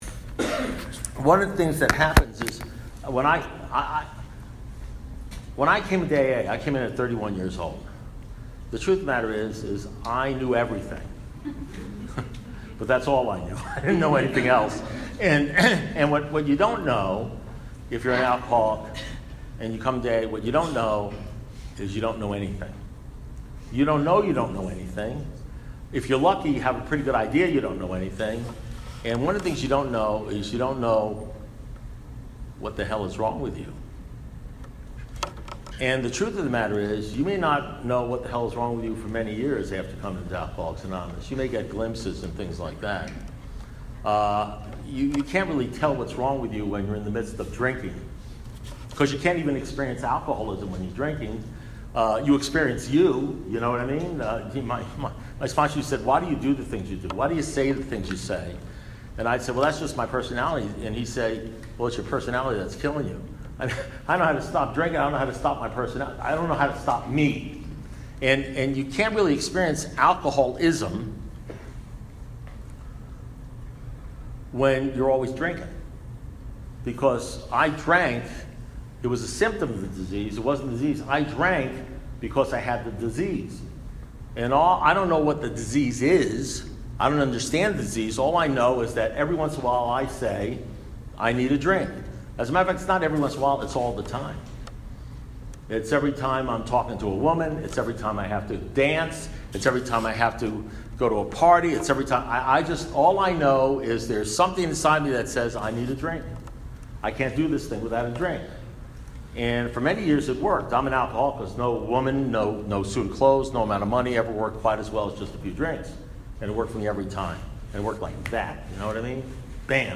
Alcoholics Anonymous Speaker Recordings
at the November 18th Annual Calgary Primary Purpose Roundup in Canada